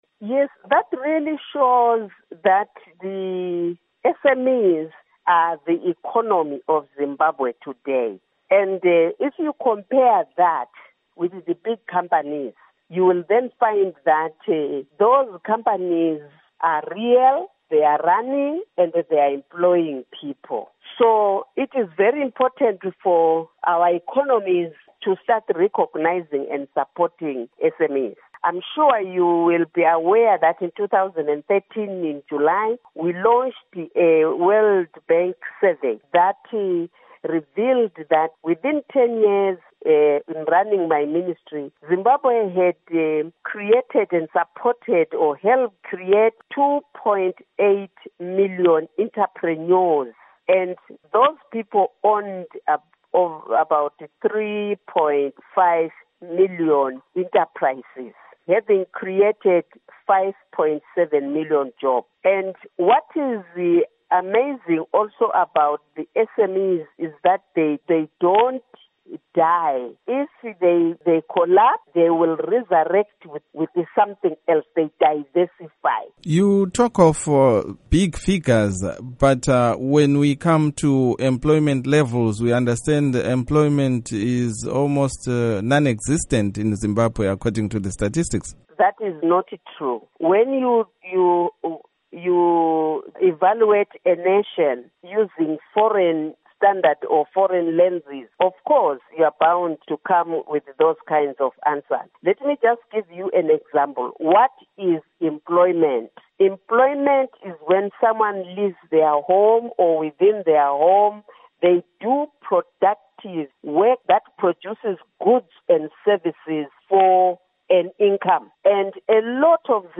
In an interview with Studio 7, Nyoni said indications are that small businesses would in 10 years dominate the business sector in the country.
Interview With Sithembiso Nyoni